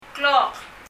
パラオ語 PALAUAN language study notes « eraser 消しゴム door ドア » clock 時計 klok [klɔk] 英） clock 日） 時計 Leave a Reply 返信をキャンセルする。